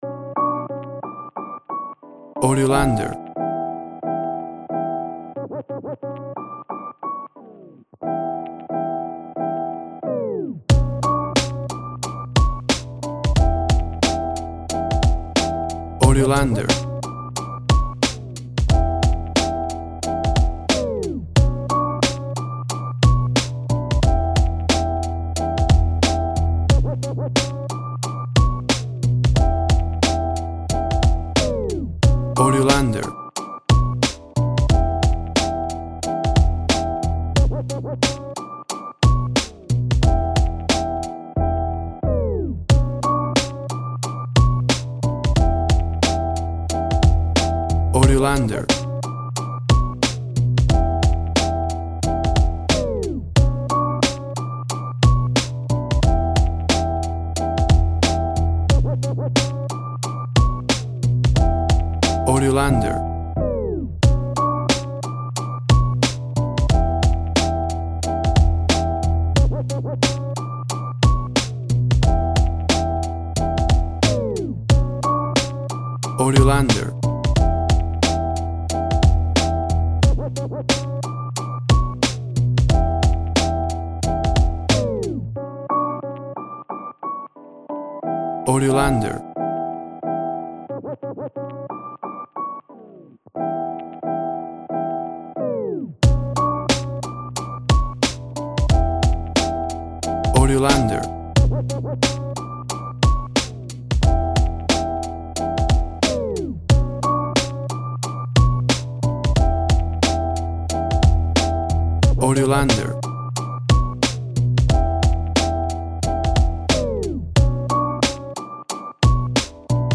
WAV Sample Rate 16-Bit Stereo, 44.1 kHz